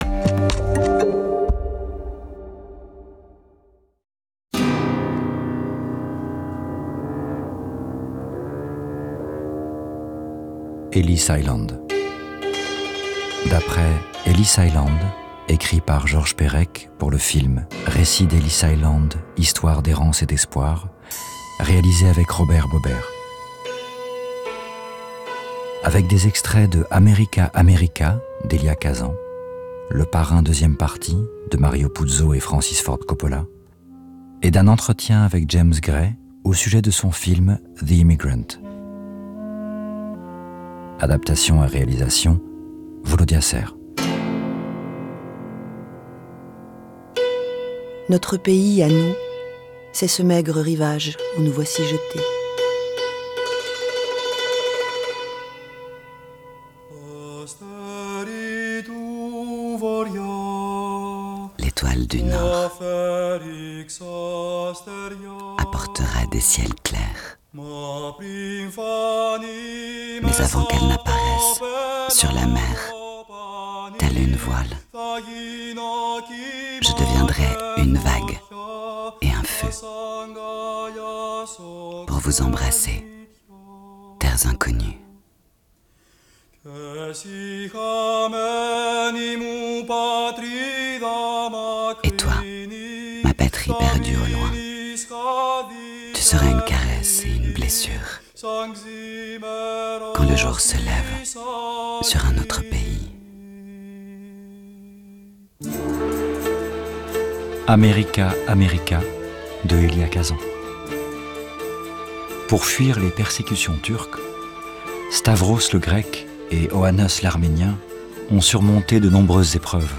Adaptation radiophonique du texte écrit par Georges Perec, décrivant l’île par où ont transité près de 16 millions d’émigrants à la fin du XIXe-début du XX...